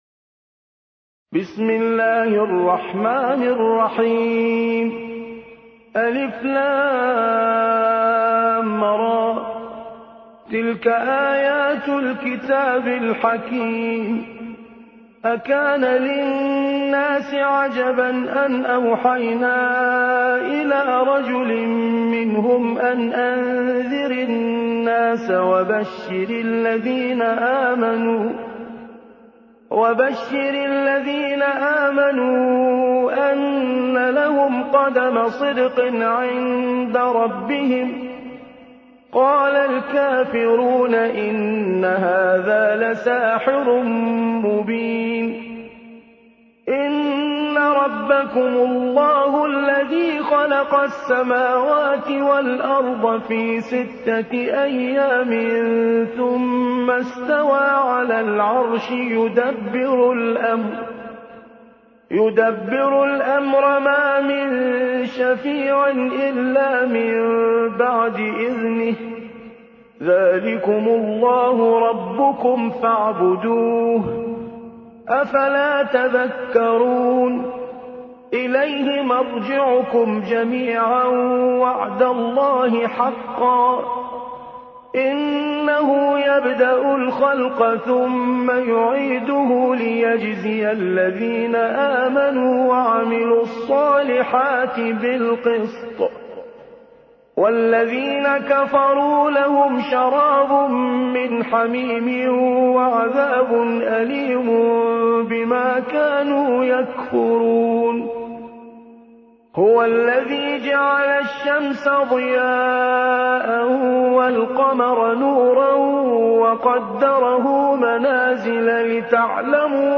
10. سورة يونس / القارئ